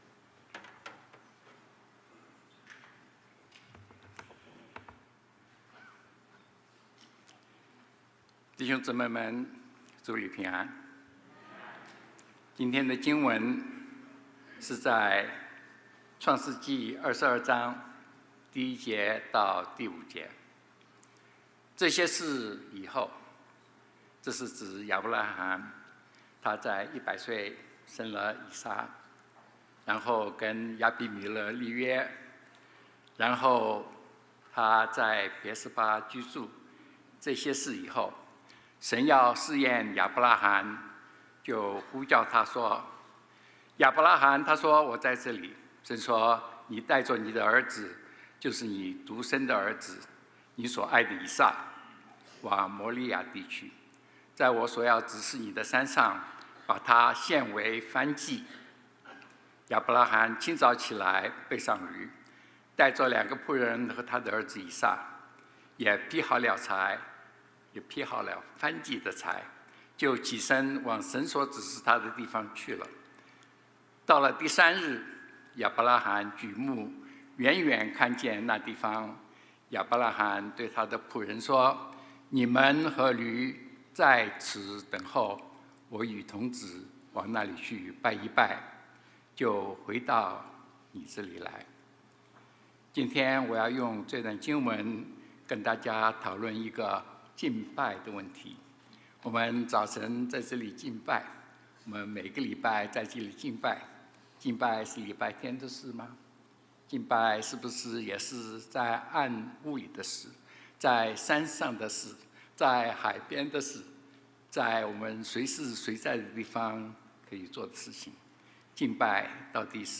RCCC Sermon On the Net